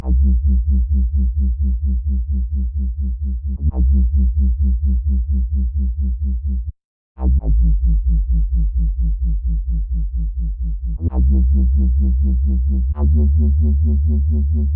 标签： 150 bpm Dubstep Loops Bass Wobble Loops 2.15 MB wav Key : F
声道立体声